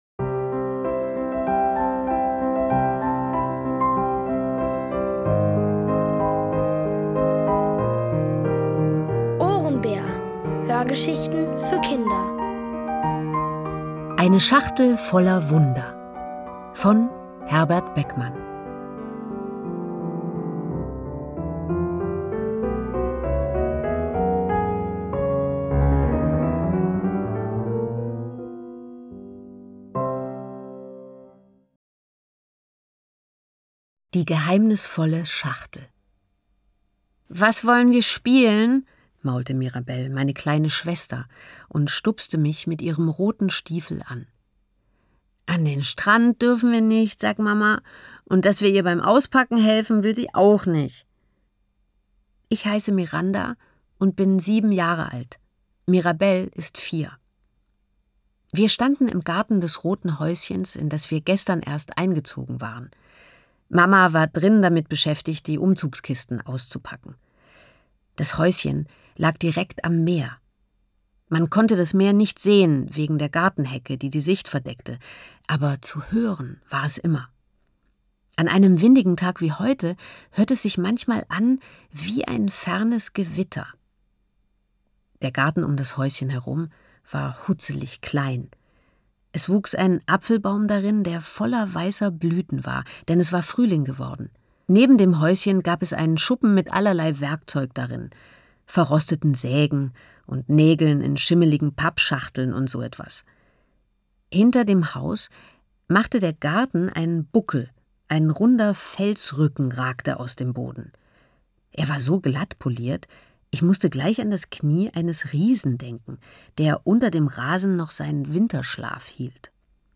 OHRENBÄR – das sind täglich Hörgeschichten für Kinder zwischen 4 und 8 Jahren. Von Autoren extra für die Reihe geschrieben und von bekannten Schauspielern gelesen.